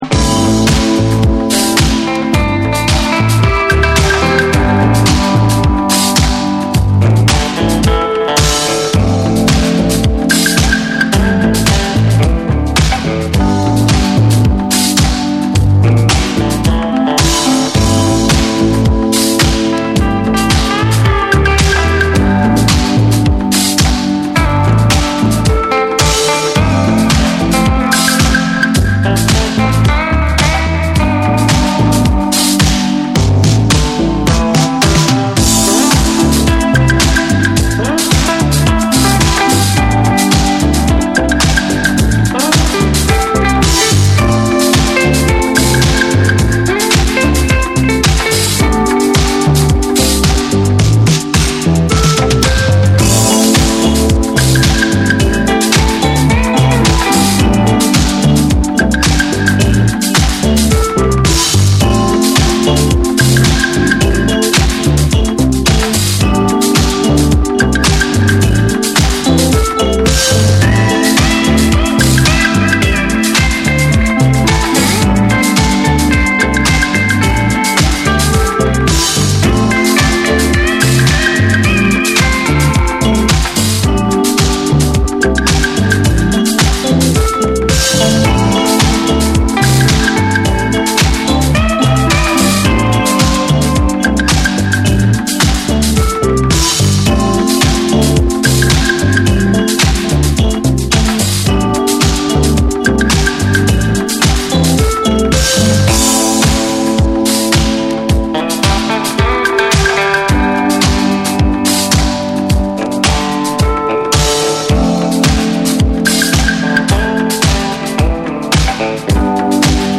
多幸感溢れる煌めくシンセ、ギターが壮大に舞いながら展開するバレアリック・ナンバーを収録。
TECHNO & HOUSE